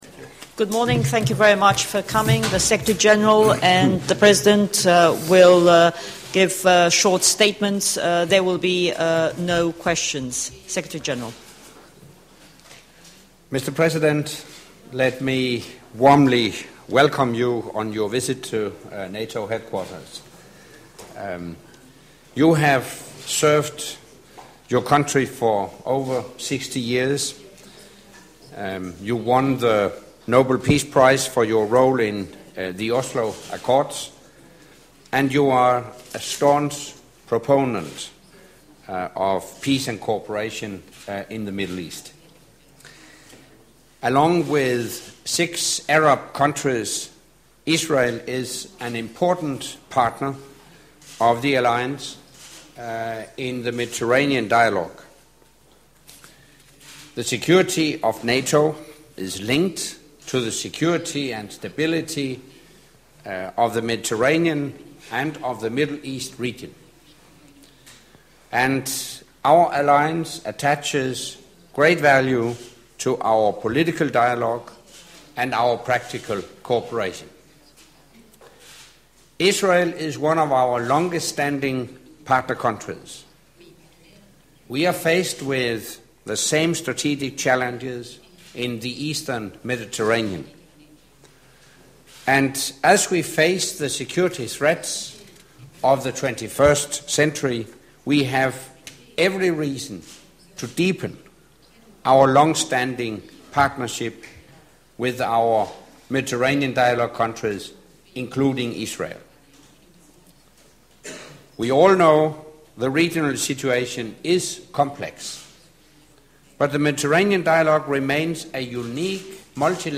Joint press point with the President of Israel, Mr. Shimon Peres and NATO Secretary General Anders Fogh Rasmussen